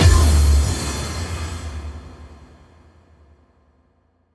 Index of /musicradar/cinematic-drama-samples/Impacts
Impact 05.wav